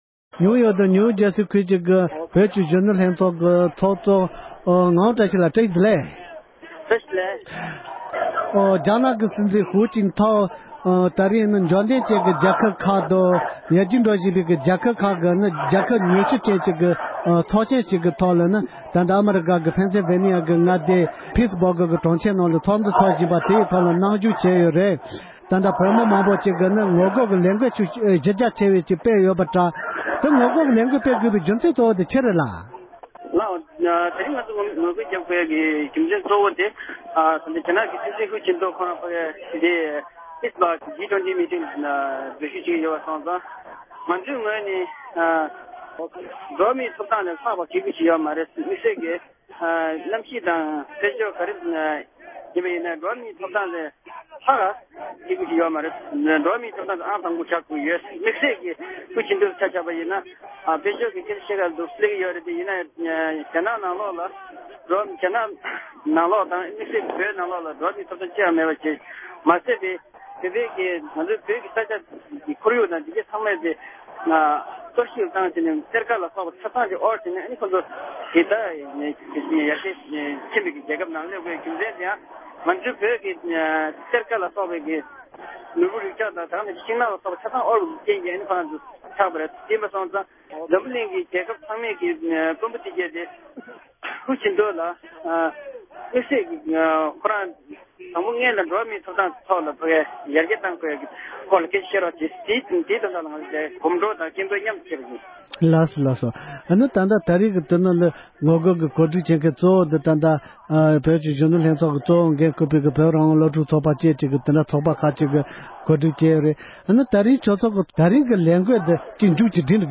འབྲེལ་ཡོད་མི་སྣ་ཁག་ལ་བཀའ་འདྲི་ཞུས་པ་ཞིག